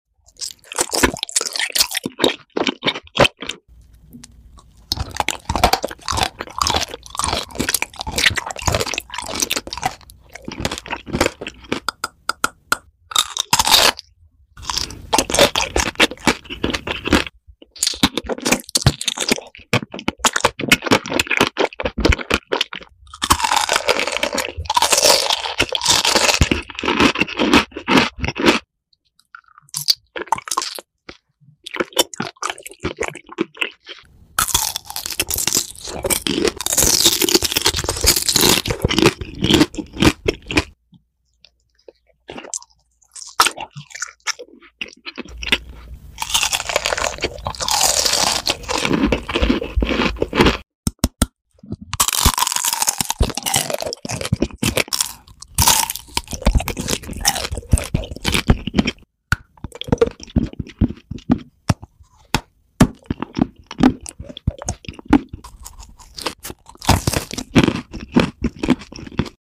ASMR! 😋😋😋 Yummy . sound effects free download